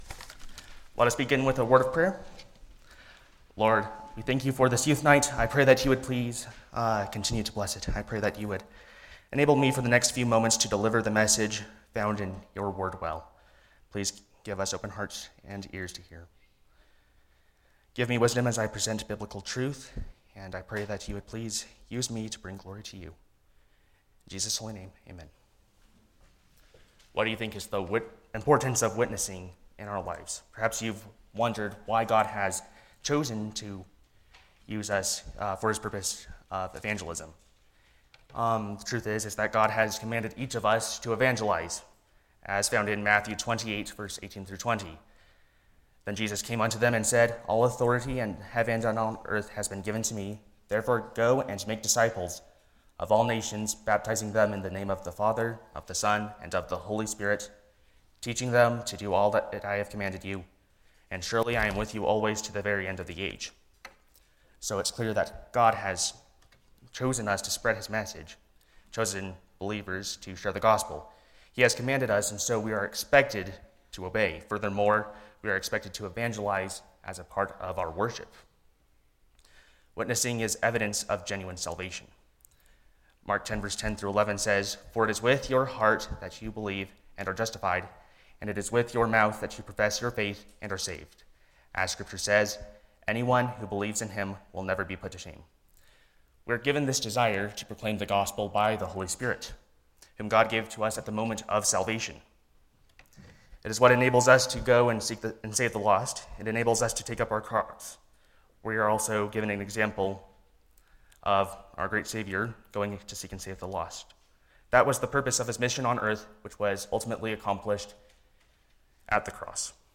Youth Night